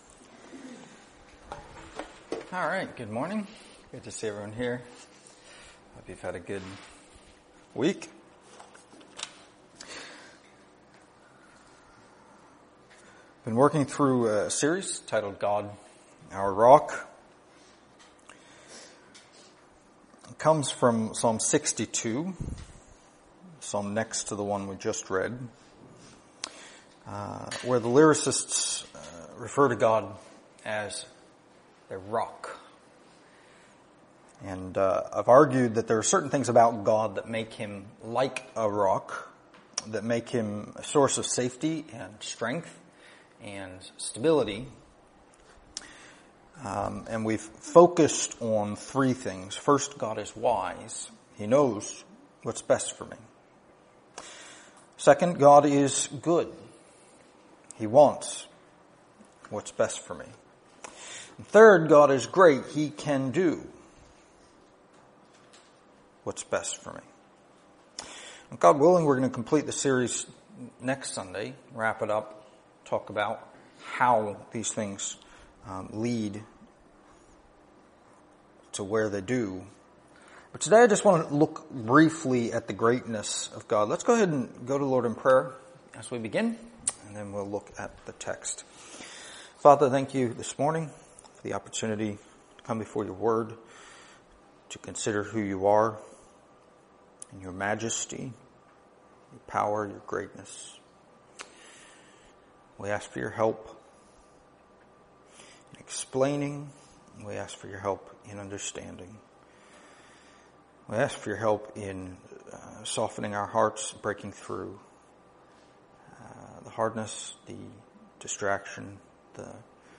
Download mp3 Previous Sermon of This Series Next Sermon of This Series